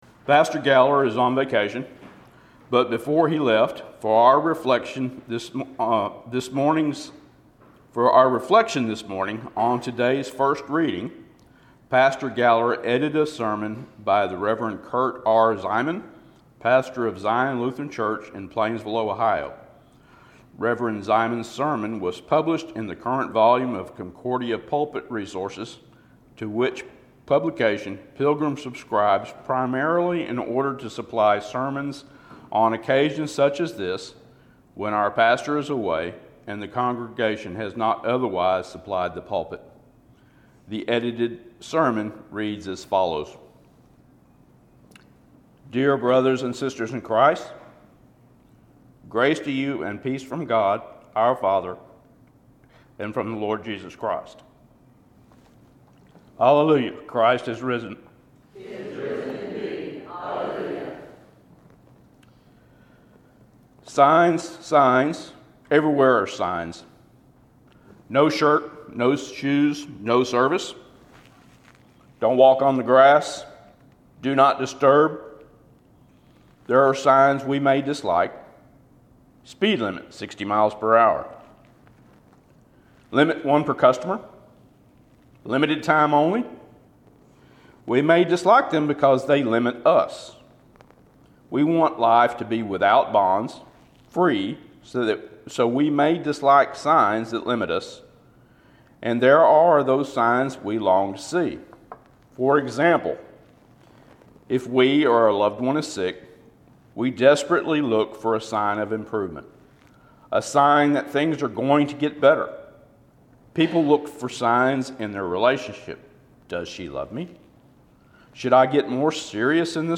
the-easter-signs.mp3